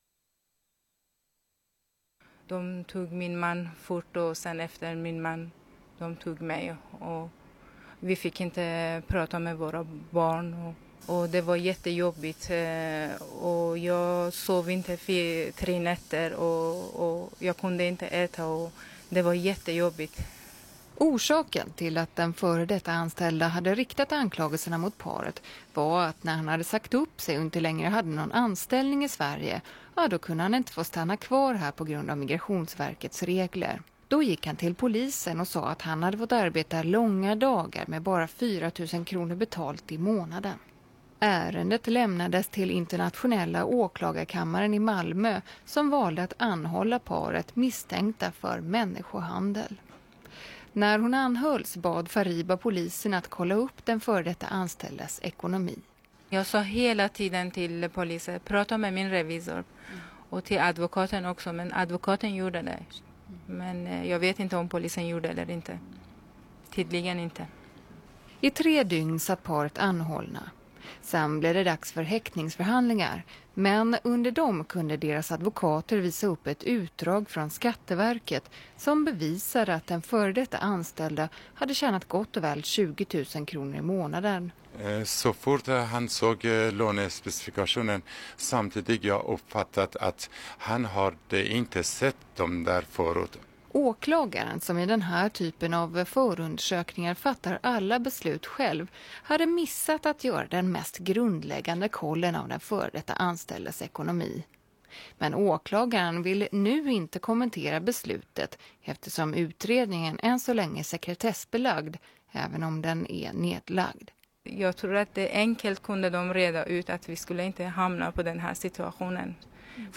Artikeln på SR och Ljudfil med intervju